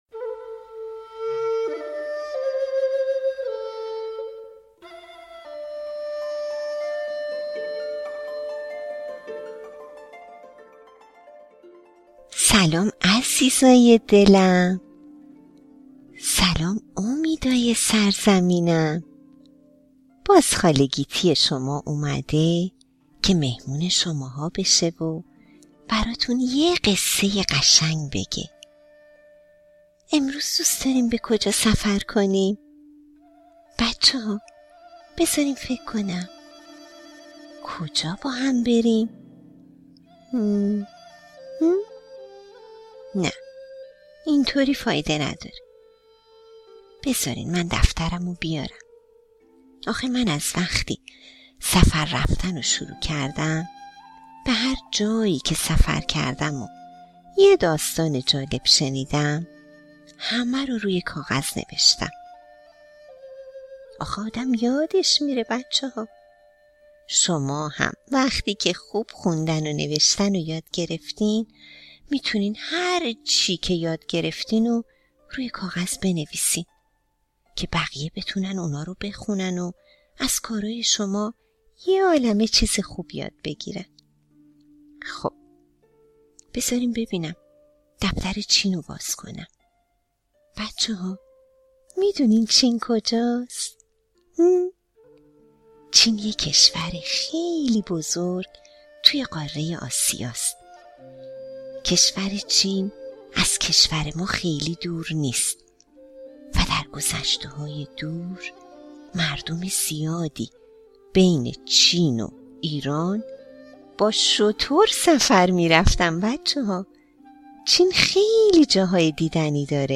قصه کودکانه صوتی کوهکن چینی
قصه-صوتی-کوهکن-چینی.mp3